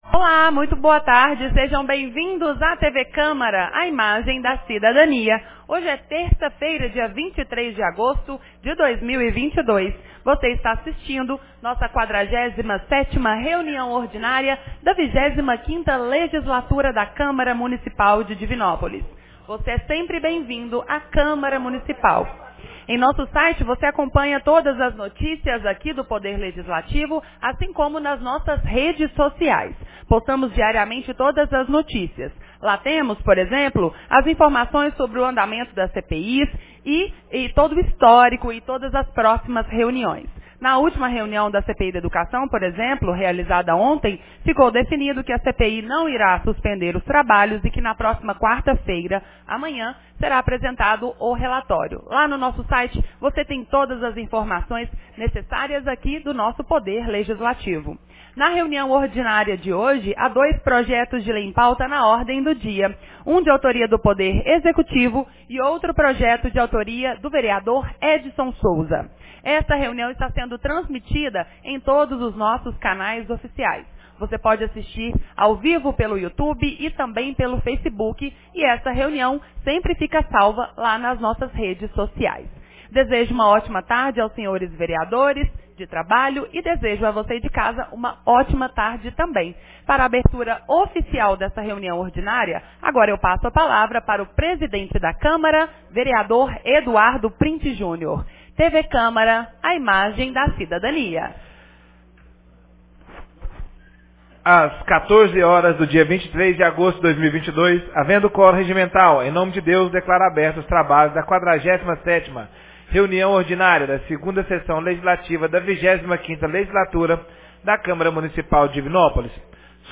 47ª Reunião Ordinária 23 de agosto de 2022